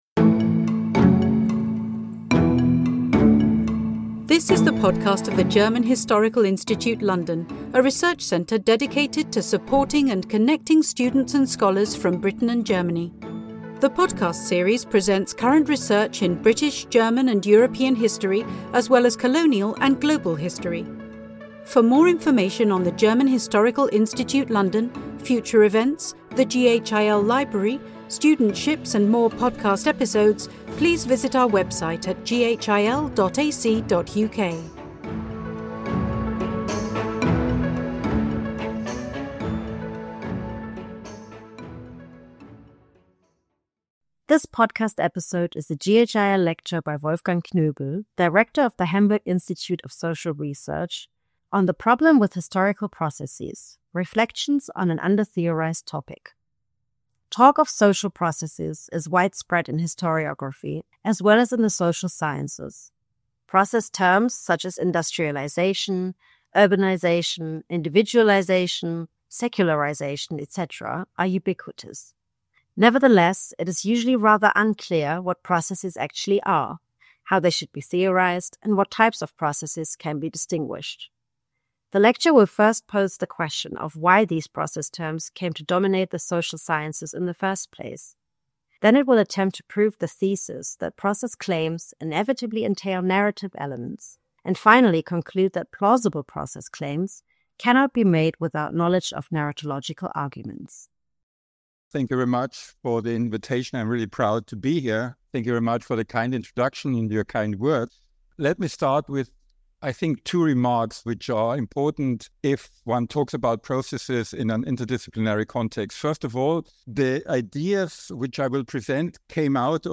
Special lecture